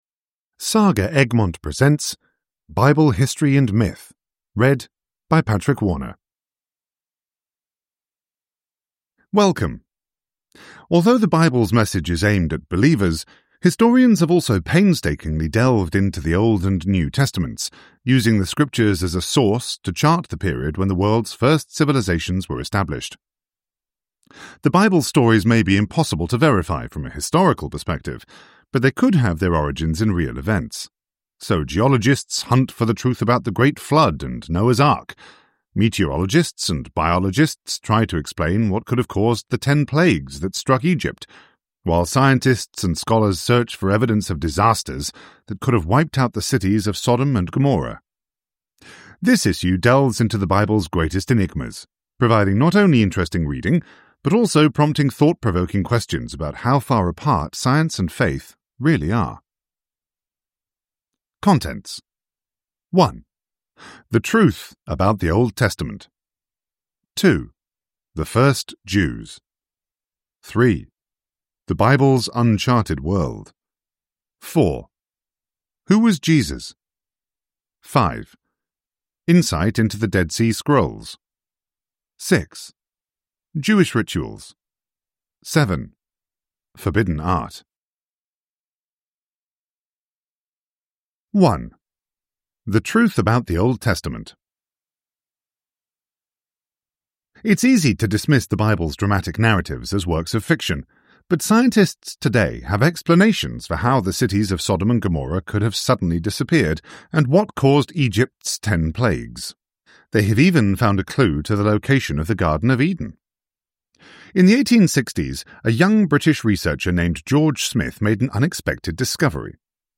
Bible History and Myth – Ljudbok